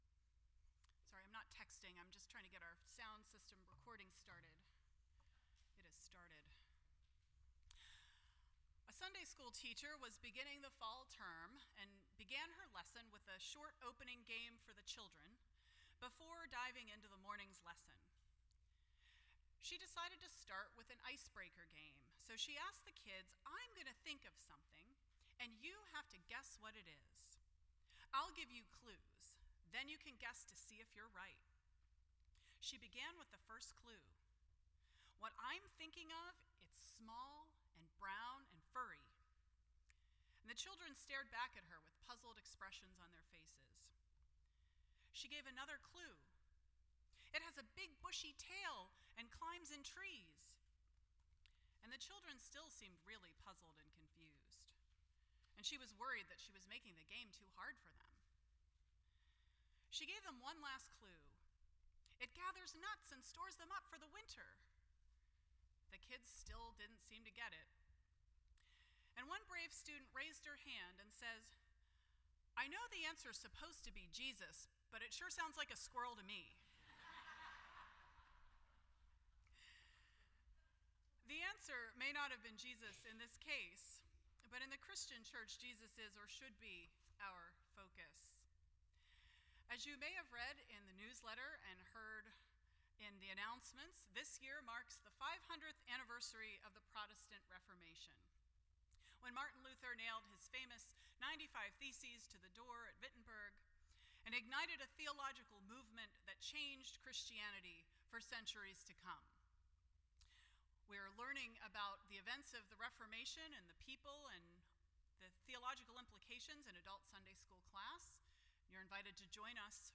The Five Solas (Onlys) of the Protestant Reformation Service Type: Sunday Morning %todo_render% Share This Story